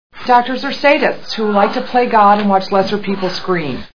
Juno Movie Sound Bites